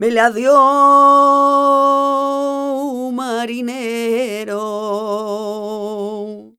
46b22voc-g#.aif